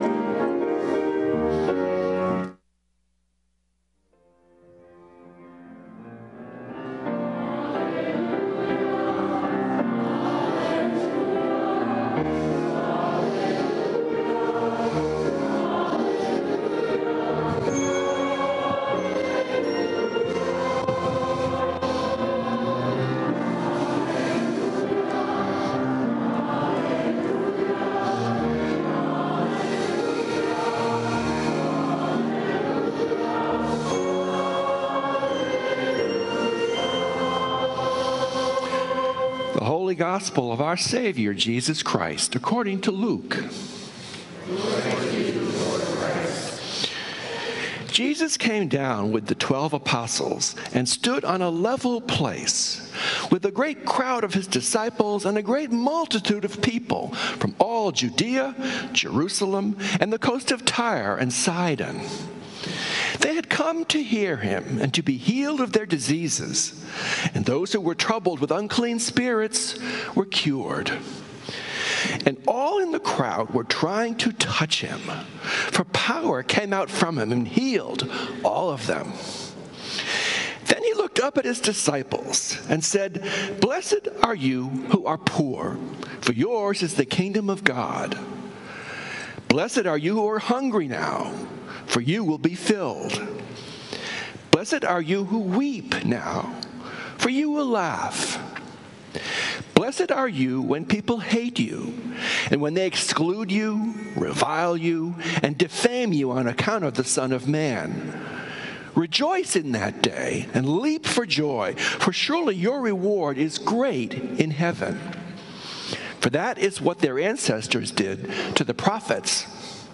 Sermons from St. Columba's in Washington, D.C. She says, “I am broken.”